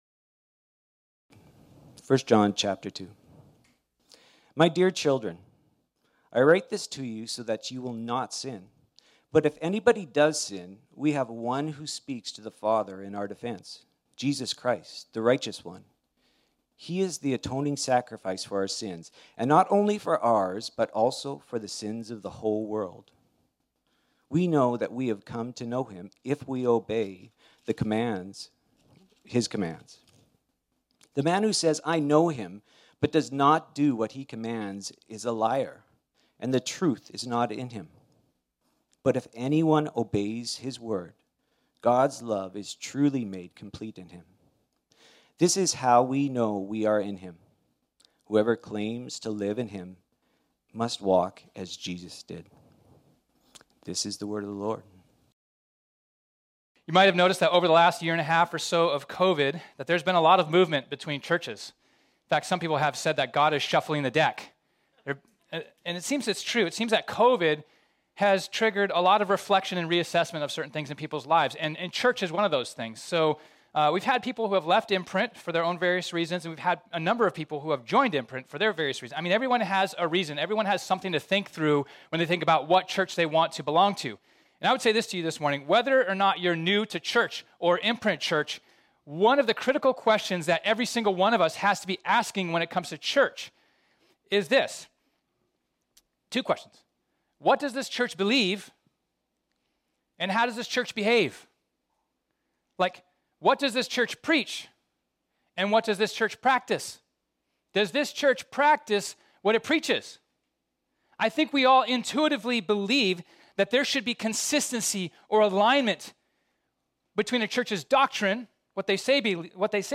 This sermon was originally preached on Sunday, September 19, 2021.